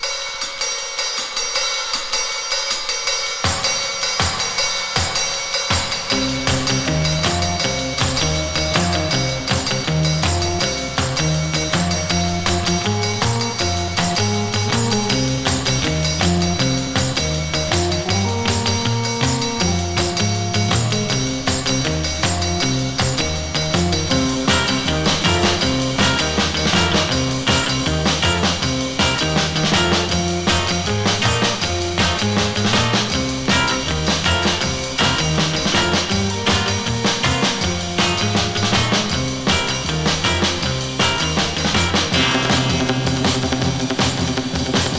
※キズ多ですが、感じさせない迫力ある音のMono盤です。
Rock, Surf, Garage, Lounge　USA　12inchレコード　33rpm　Mono